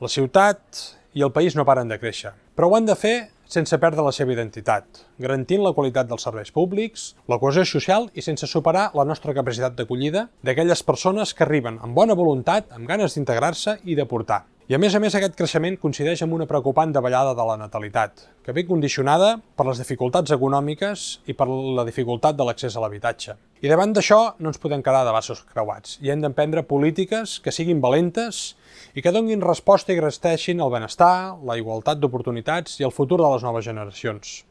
L’alcalde Marc Buch s’ha adreçat a la ciutadania en el tradicional missatge institucional de Nadal, des de l’edifici de l’Ajuntament amb la plaça il·luminada de fons.